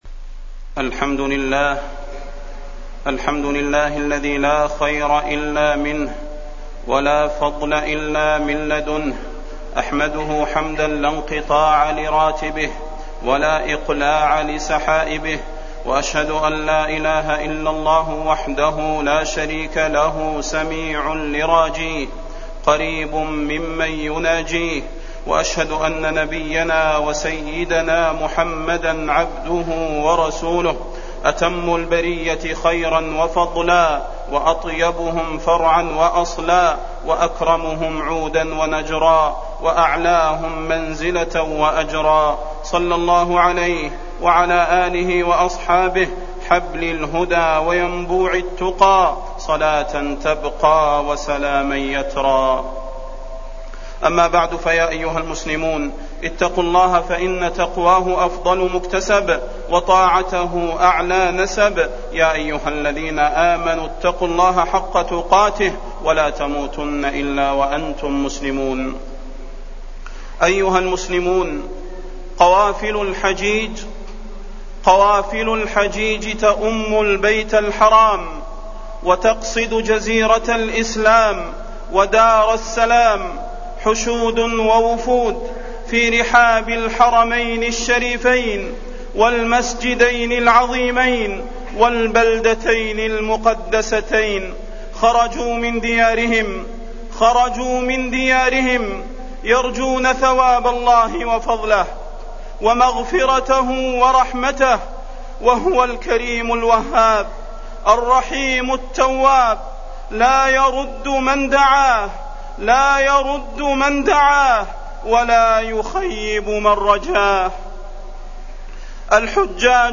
تاريخ النشر ٣٠ ذو القعدة ١٤٢٩ هـ المكان: المسجد النبوي الشيخ: فضيلة الشيخ د. صلاح بن محمد البدير فضيلة الشيخ د. صلاح بن محمد البدير وصايا للحجاج وفضل العشر وأعمالها